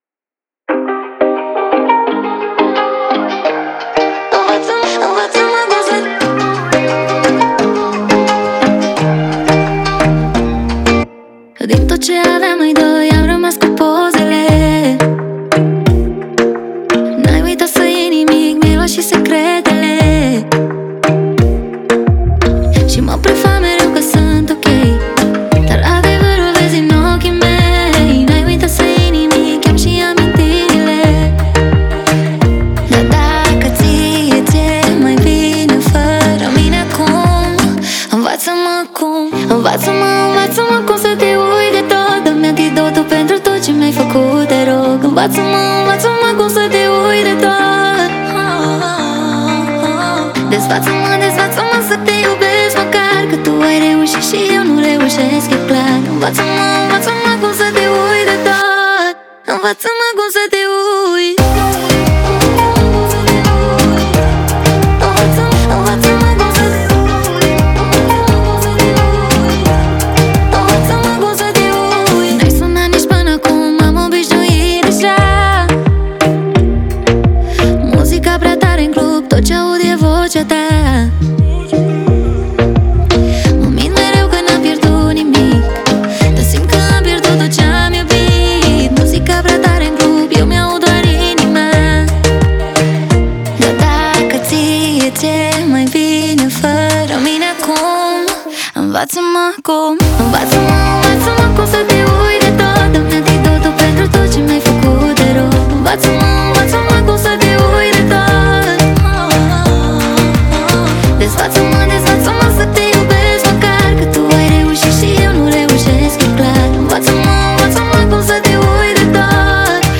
это эмоциональный трек в жанре поп